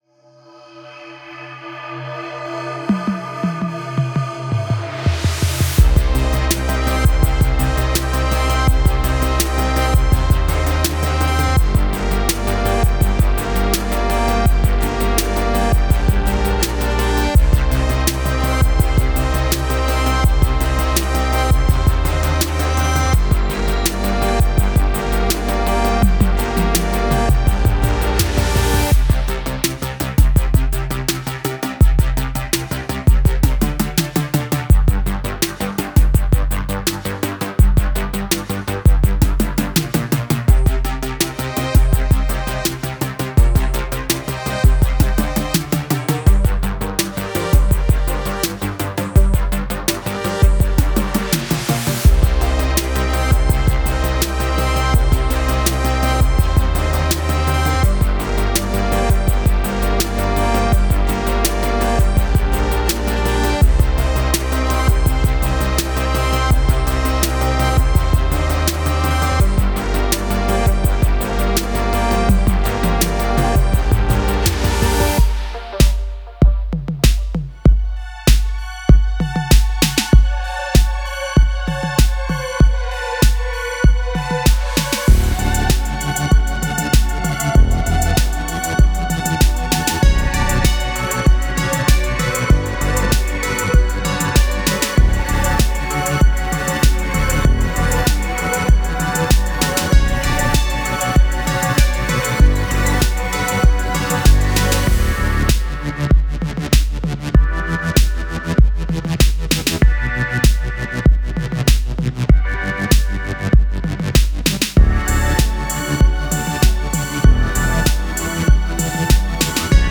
synthwave loops and multisamples
massive 80s Synthwave sounds!
MP3 DEMO